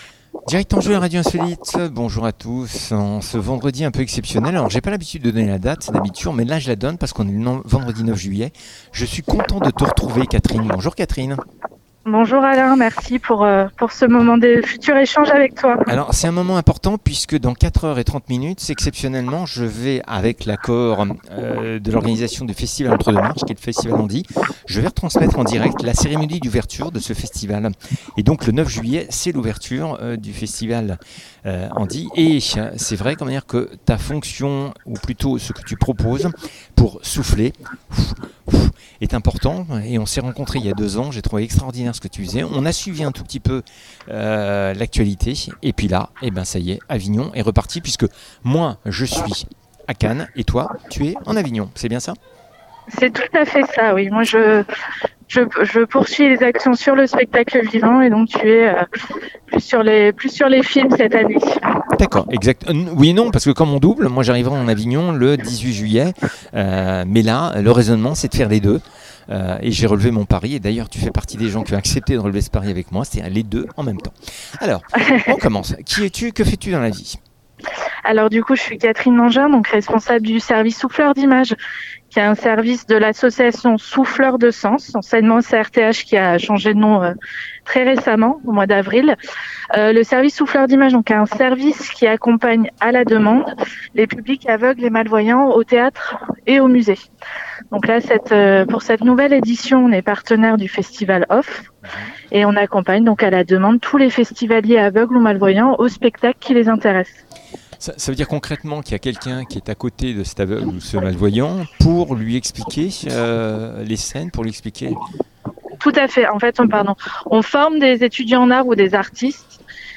Souffleurs d'Images en direct du Off d'Avignon 21